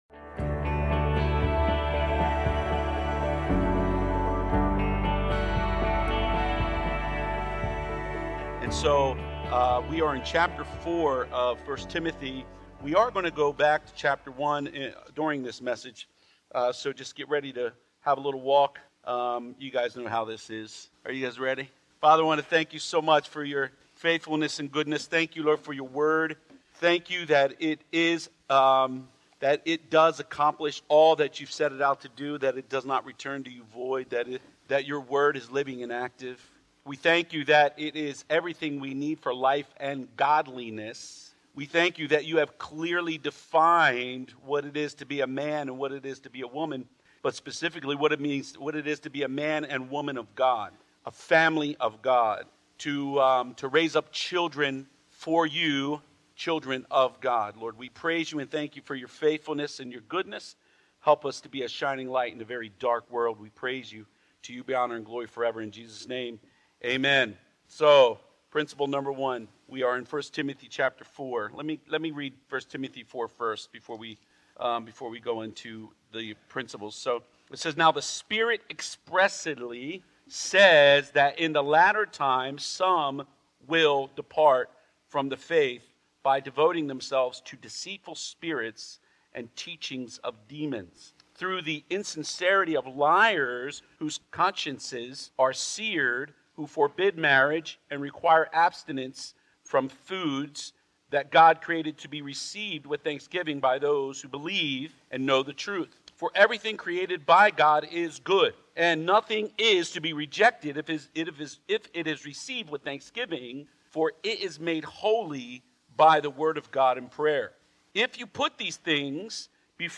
Sermons | Mount Eaton Church